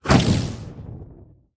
Minecraft Version Minecraft Version 25w18a Latest Release | Latest Snapshot 25w18a / assets / minecraft / sounds / entity / shulker / shoot4.ogg Compare With Compare With Latest Release | Latest Snapshot
shoot4.ogg